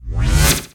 Polaris/sound/effects/uncloak.ogg